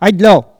Il crie pour chasser le chien ( prononcer le cri )
Maraîchin